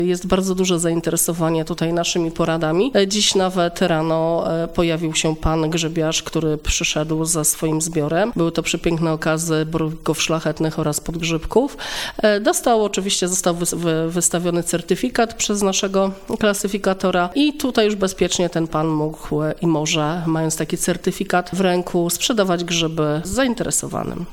Od poniedziałku do piątku w godzinach od 7:15 do 15:00 osoby zbierające grzyby mogą przyjść na porady dotyczące jakości grzybów i tego jak bezpiecznie je zbierać – mówi Państwowy Powiatowy Inspektor Sanitarny w Stargardzie dr inż. Irena Agata Łucka.